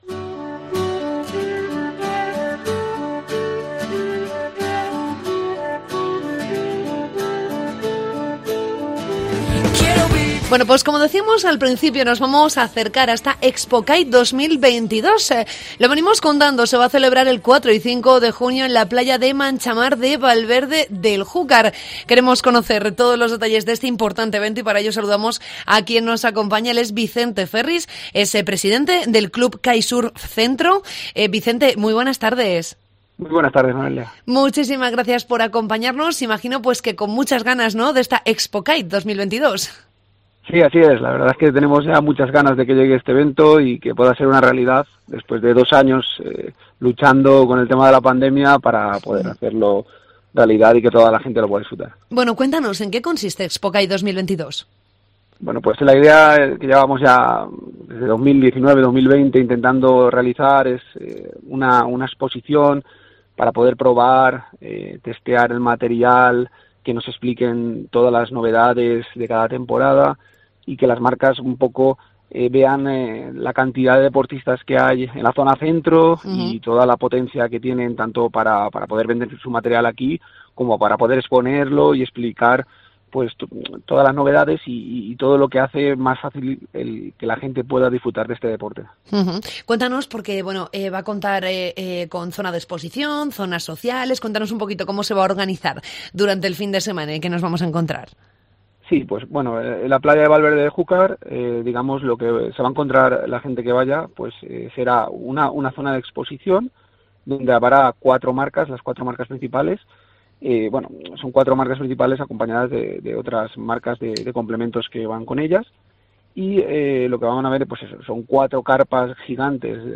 ctv-bga-entrevista-expo-kite